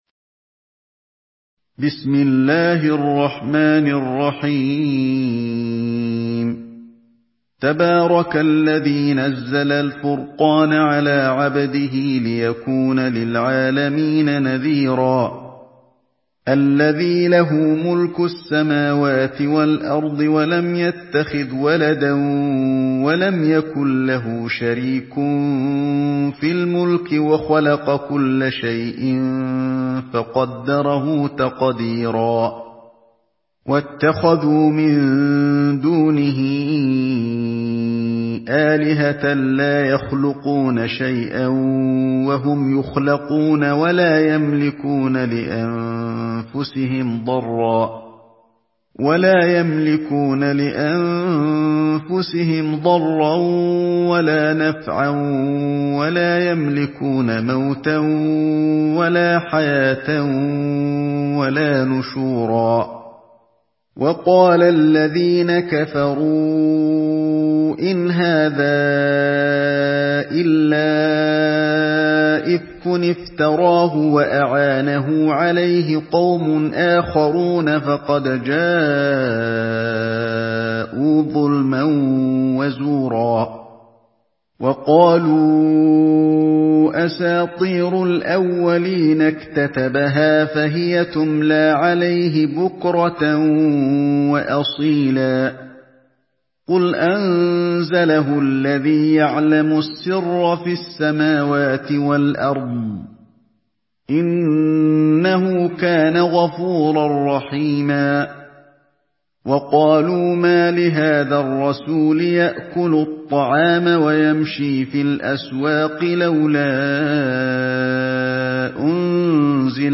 Surah الفرقان MP3 by علي الحذيفي in حفص عن عاصم narration.